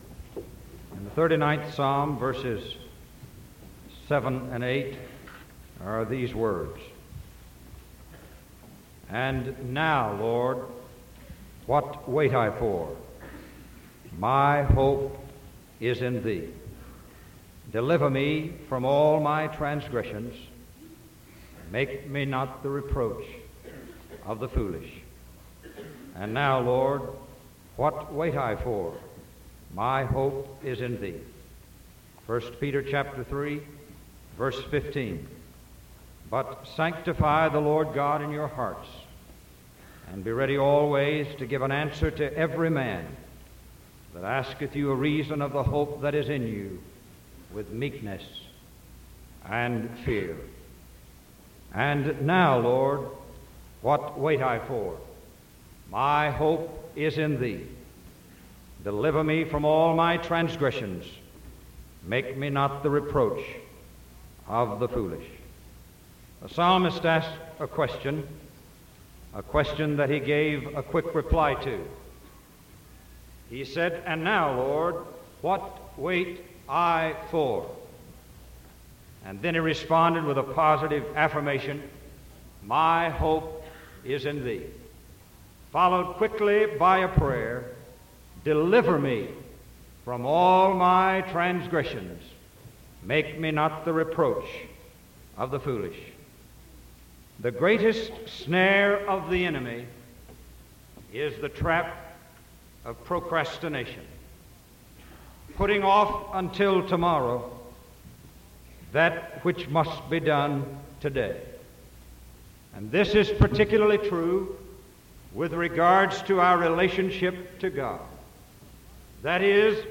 Sermon September 8th 1974 PM